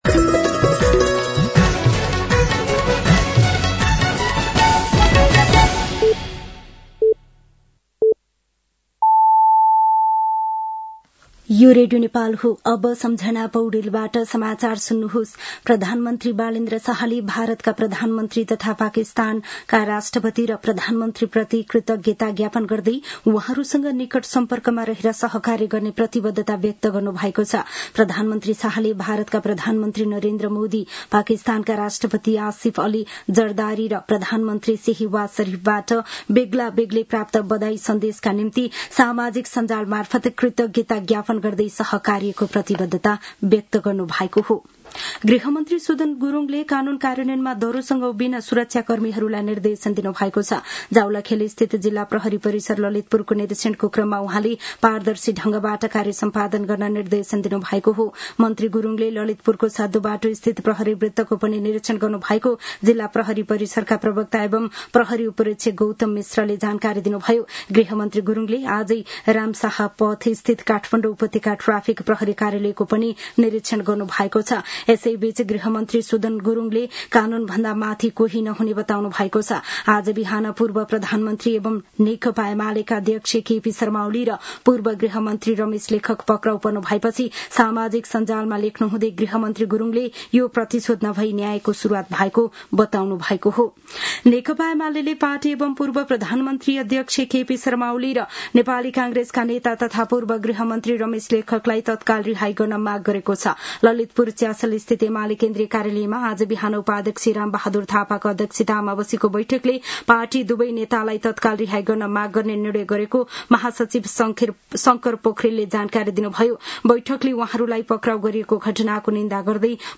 साँझ ५ बजेको नेपाली समाचार : १४ चैत , २०८२
5.-pm-nepali-news-1-5.mp3